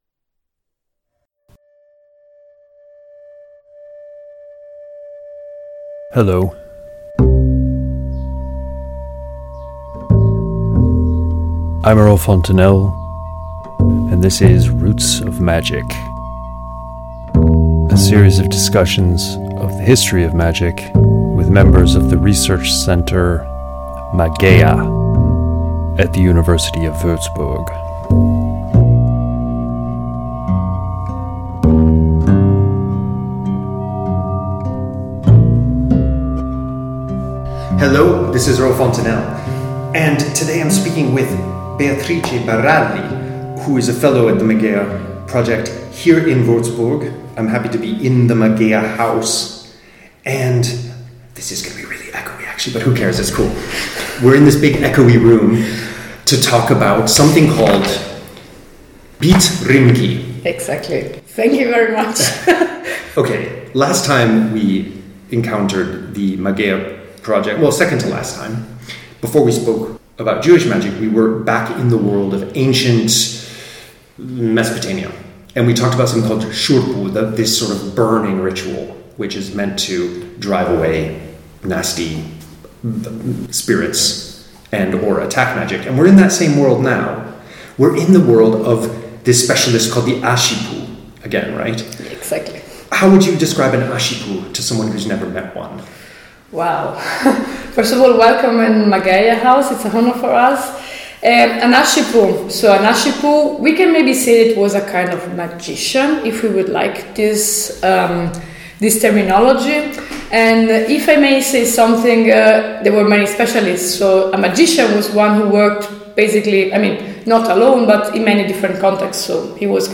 Roots of Magic Interview 8
das Mageiahaus in Würzburg (wonderful but prone to echo, as you will hear in the interview)